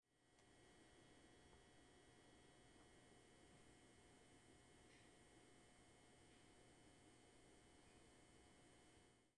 静电干扰器（Elektrosmog
描述：我在对我的客厅进行采样时记录了这个声音 :).它来自我们的Gigaset电话，我的Tascam DR07 mkII可以她和记录它。 :)
标签： 现场录音 噪音 嗡嗡作响 电力
声道立体声